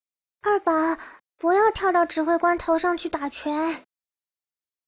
贡献 ） 协议：Copyright，人物： 碧蓝航线:平海语音 2021年2月4日